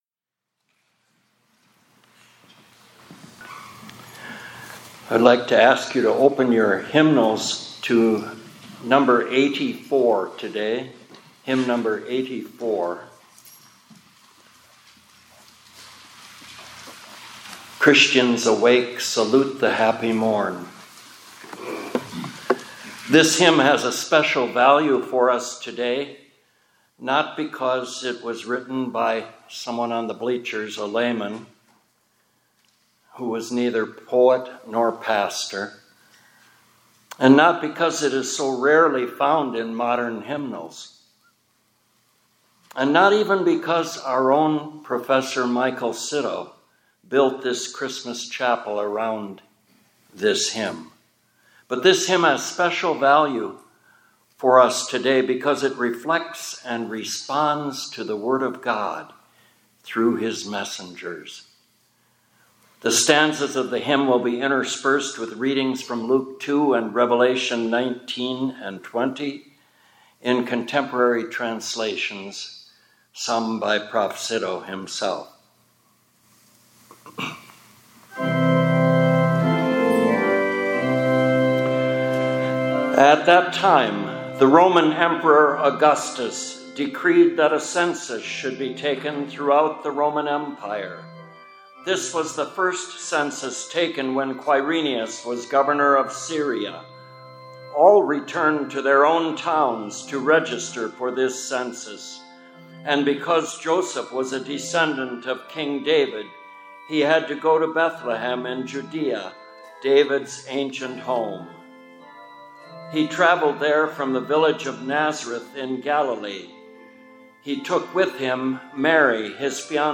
2025-12-10 ILC Chapel — Christians, Awake